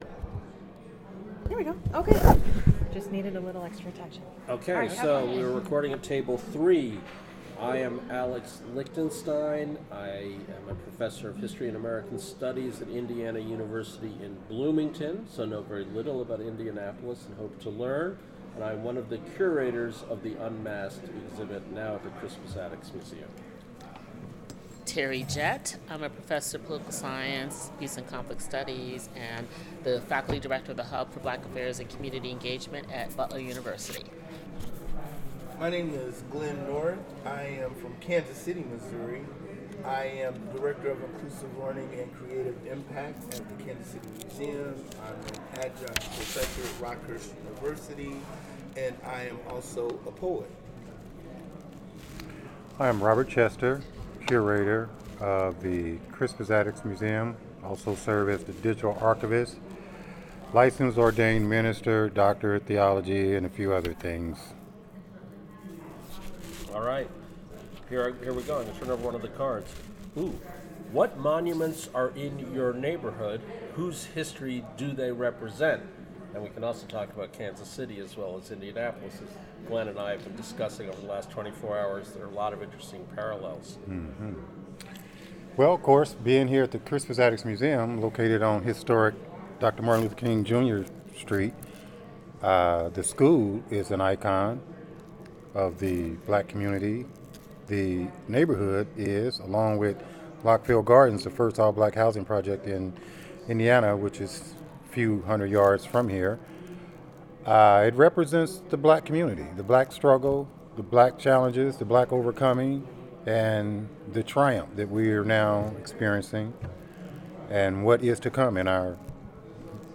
sound recording-nonmusical
Genre oral history